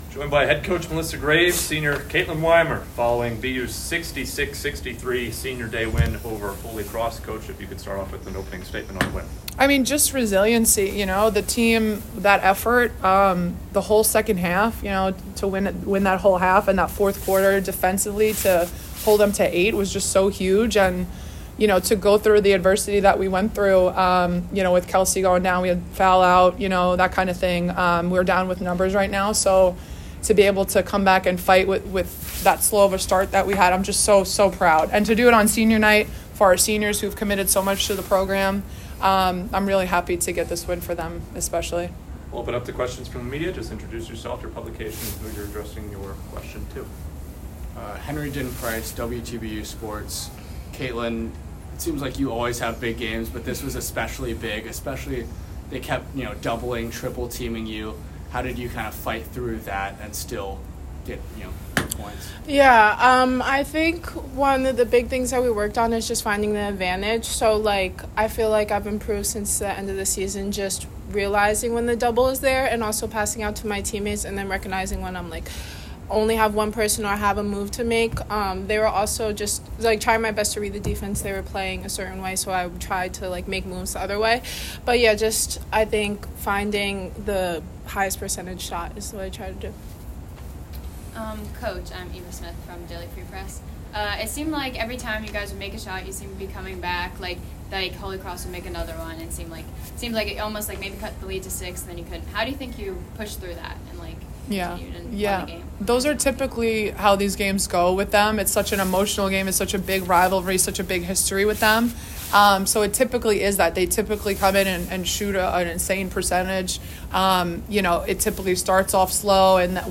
Holy Cross Postgame Press Conference